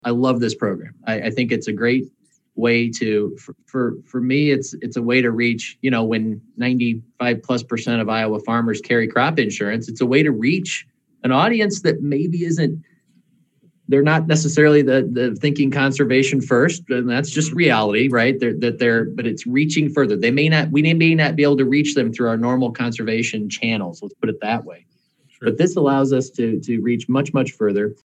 Iowa Secretary of Agriculture Mike Naig was a special guest for the Iowa Farmers Union?s recent virtual lunch and learn program.